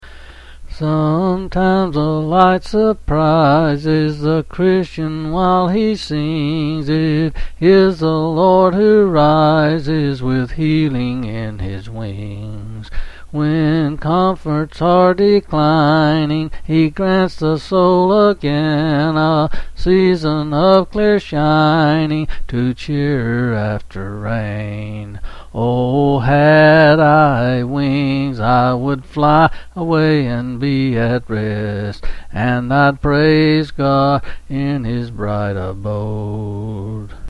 Quill Selected Hymn